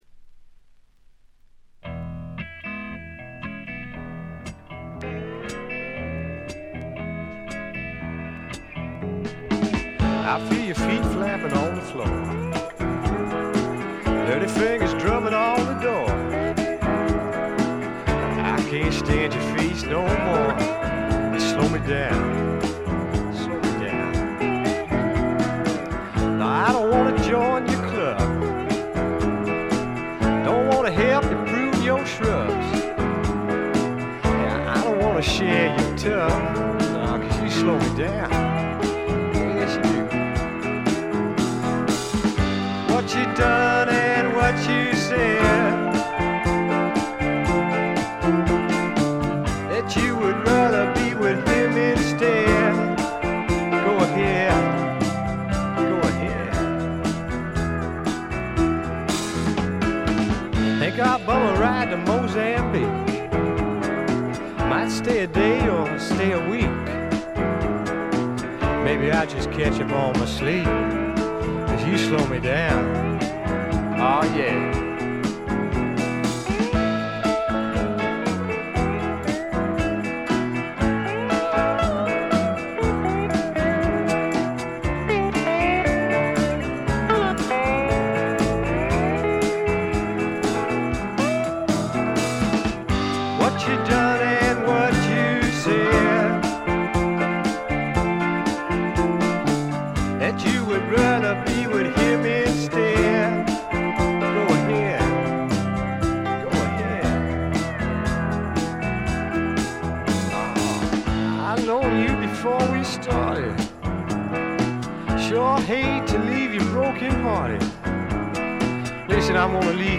わずかなチリプチ程度。
ジャケットはまるで激渋の英国フォークみたいですが、中身はパブロック／英国スワンプの裏名盤であります。
カントリー風味、オールド・ロックンロールを元にスワンプというには軽い、まさに小粋なパブロックを展開しています。
試聴曲は現品からの取り込み音源です。